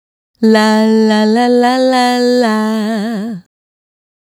La La La 110-A.wav